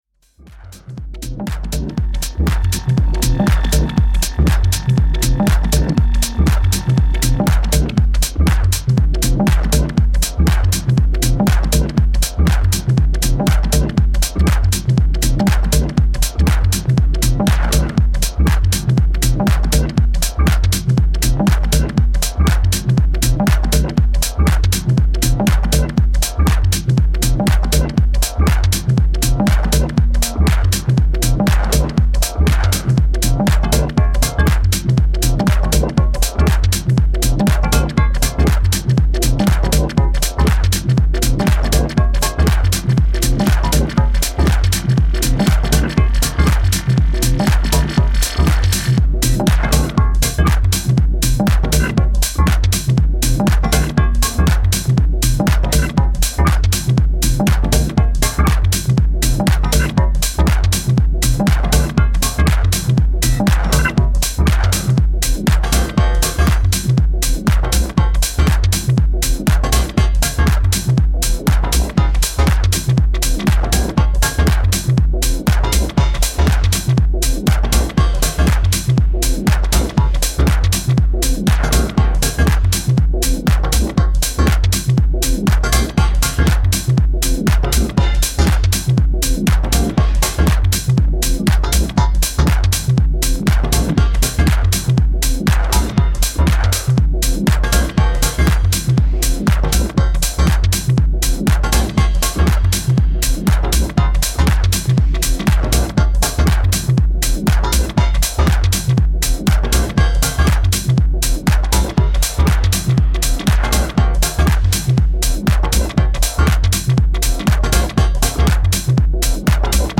smooth 100 bpm house